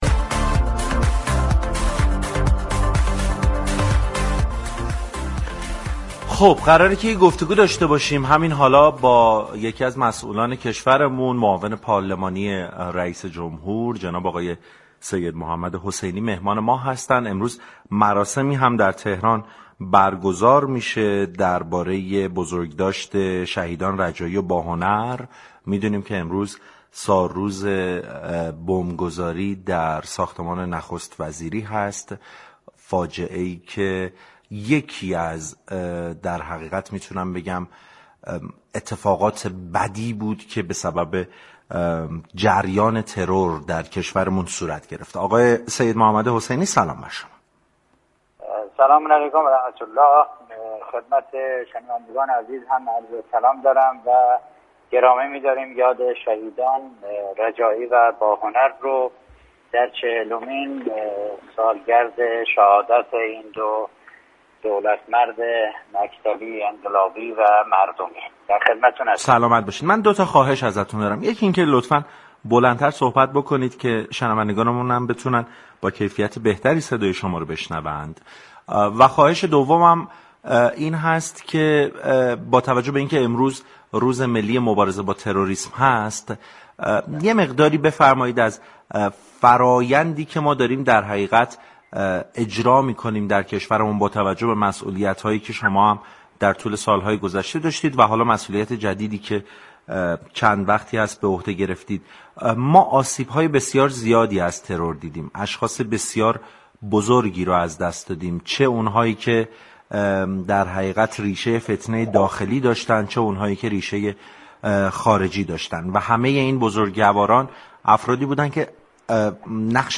به گزارش پایگاه اطلاع رسانی رادیو تهران، سیدمحمدحسینی معاون پارلمانی رئیس جمهور در گفتگو با سعادت آباد 8 شهریور به مناسبت سالروز بمب گذاری در دفتر نخست وزیری جمهوری اسلامی در سال 1360 و شهادت شهیدان رجایی و باهنر و روز ملی مبارزه با تروریسم اظهار داشت: سال 60 سال عجیبی بربی مردم ما بود و كشور ما و قربانیان زیادی از جمله مردم و مسئولان طراز اول و ائمه جمعه را در دفتر تاریخ ثبت كرد.